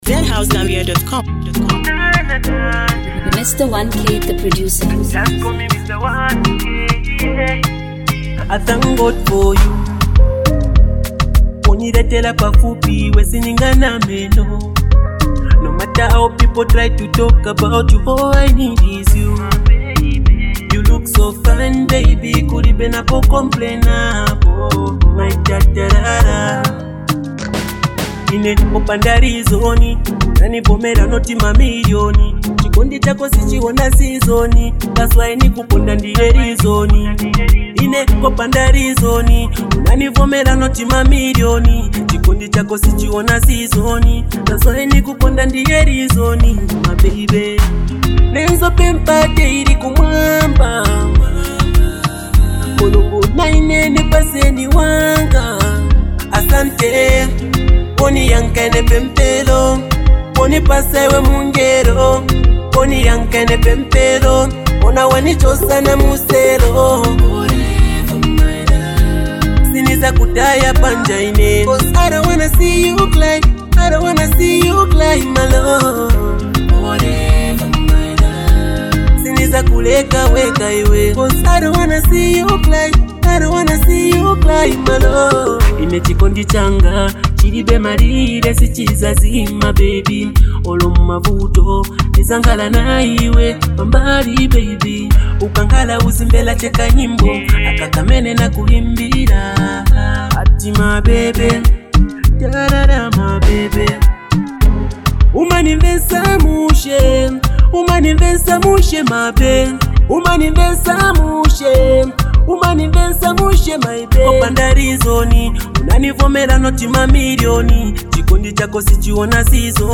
soulful masterpiece
💕 With heartfelt lyrics and smooth delivery